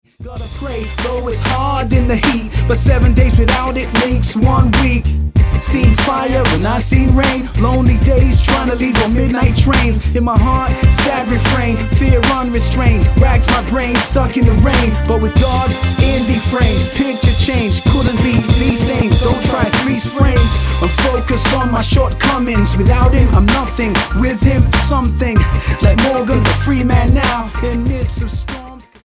London rapper
Style: Hip-Hop